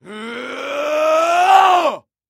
Здесь вы найдете громкие призывы воинов, ритуальные возгласы и современные мотивационные крики.